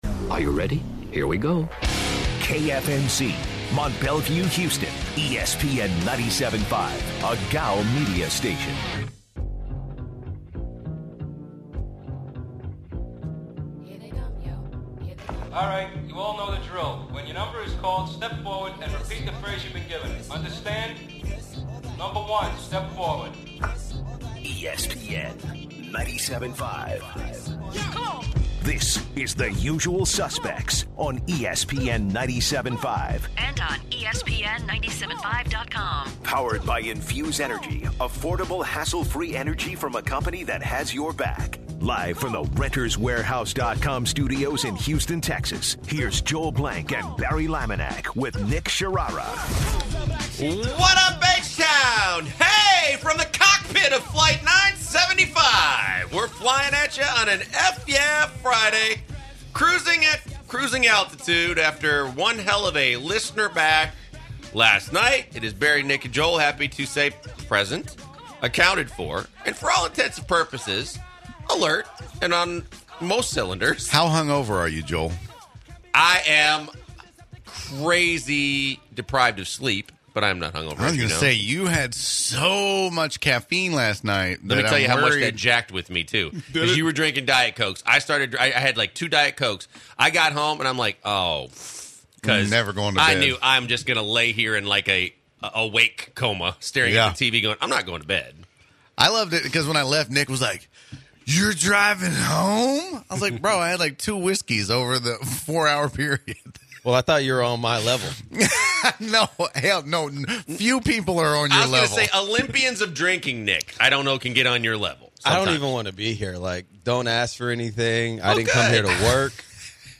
The guys take calls from guests who attended the party.